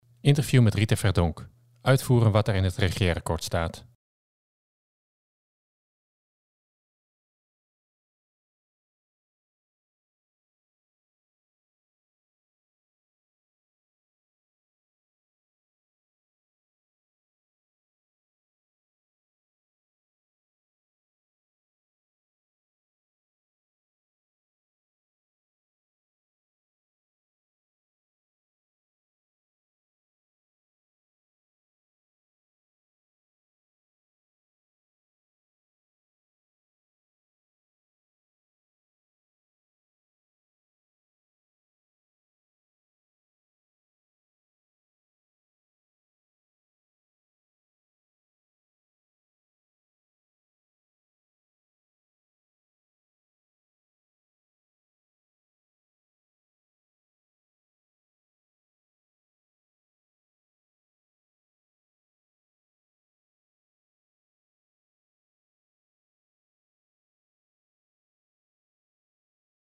Interview met Rita Verdonk